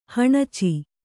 ♪ haṇaci